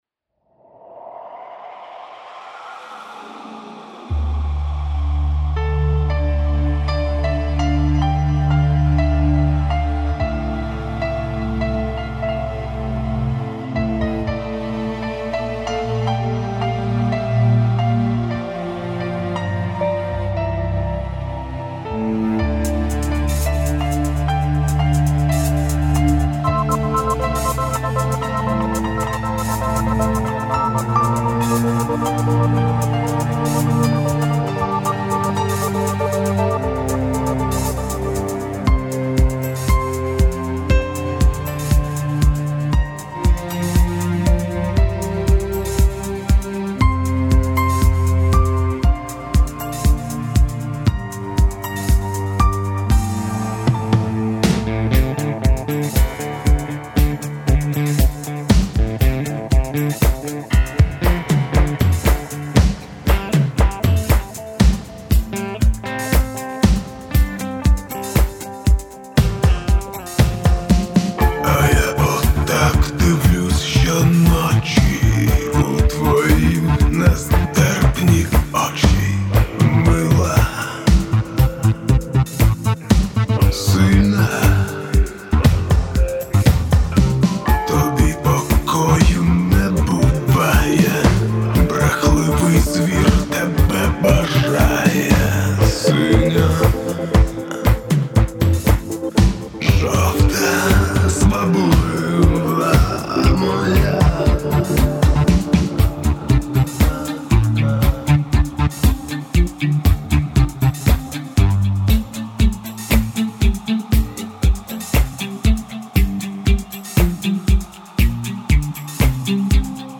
Dream PIANOttt.mp3
Рубрика: Поезія, Авторська пісня